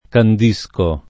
candisco [ kand &S ko ], -sci